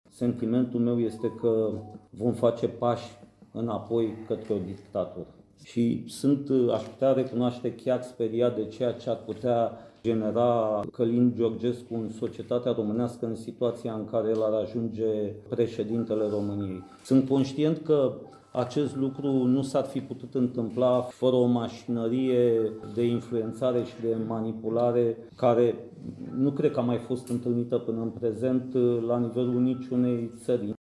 CONSTANȚA: Conferință de presă la sediul PNL. Ce subiecte au fost abordate